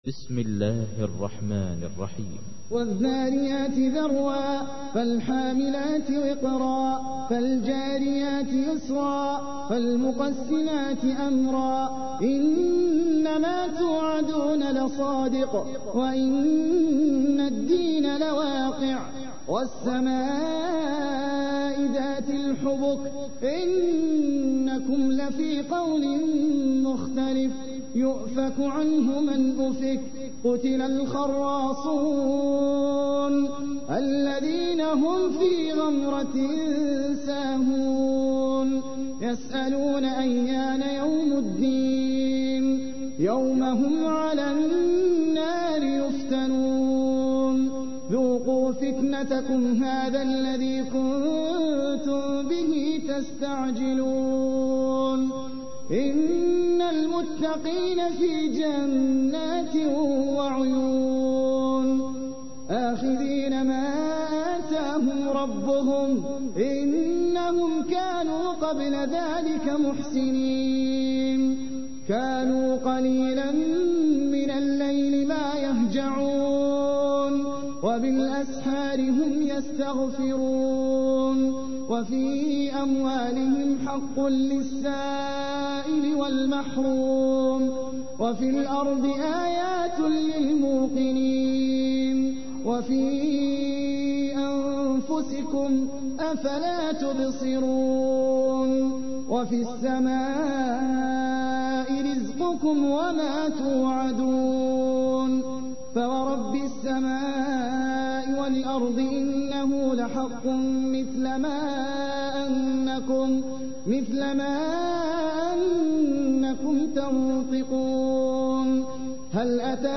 تحميل : 51. سورة الذاريات / القارئ احمد العجمي / القرآن الكريم / موقع يا حسين